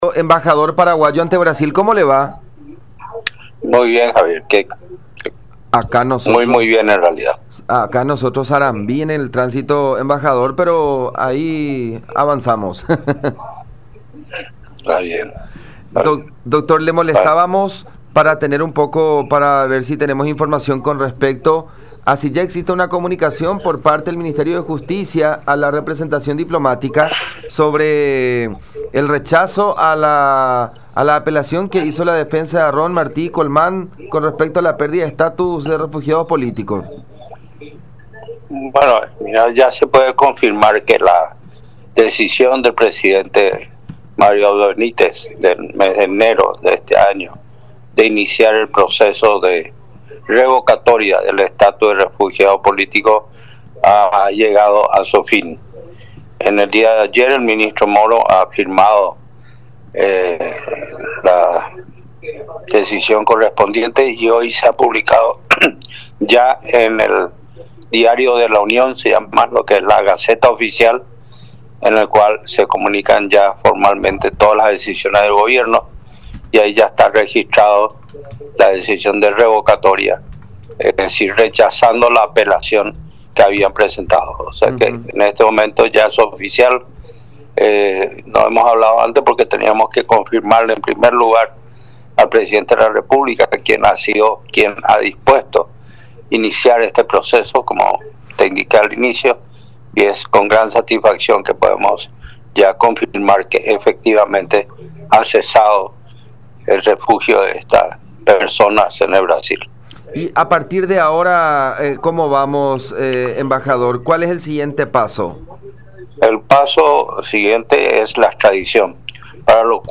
“Nosotros ya habíamos adelantado un pedido de extradición con una consulta previa que hicimos ante el Ministerio de Justicia (de Brasil) porque, estando todavía pendiente la decisión final, no teníamos que apresurarnos y presentar el pedido sin que corresponda”, explicó el diplomático en contacto con La Unión.